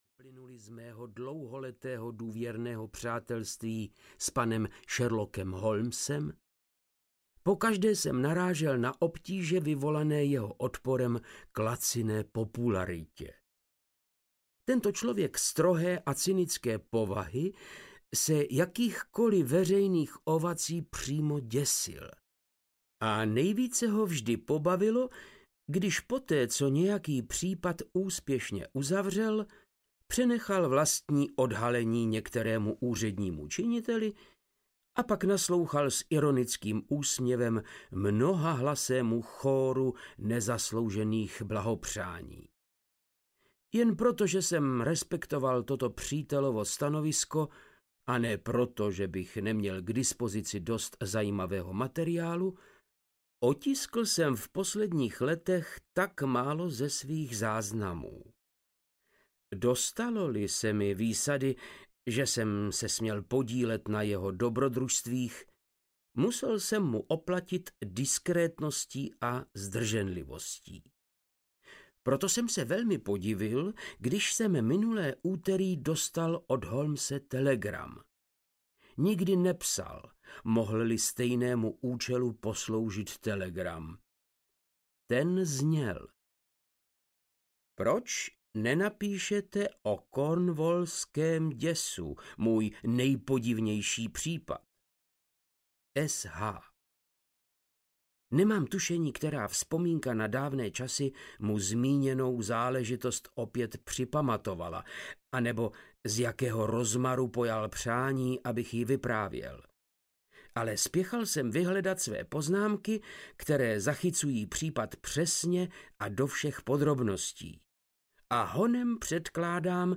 Ďáblovo kopyto audiokniha
Ukázka z knihy
• InterpretVáclav Knop